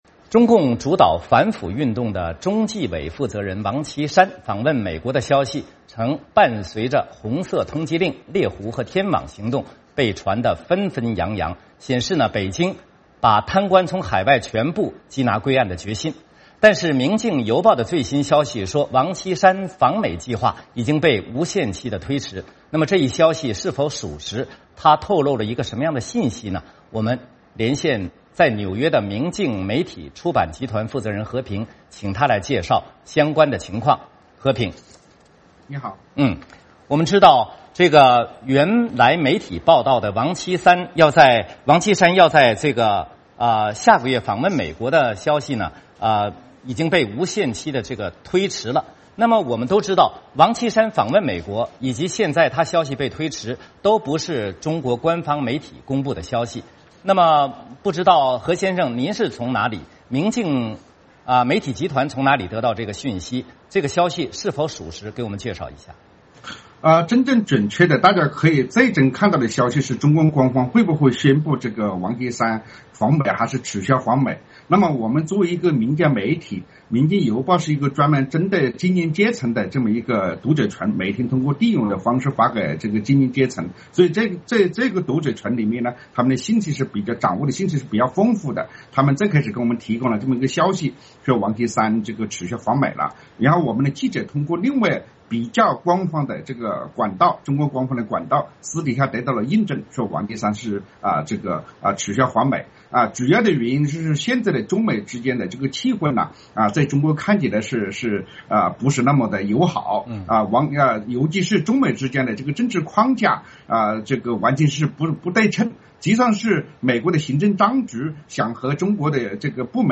VOA连线：王岐山无限期推迟访美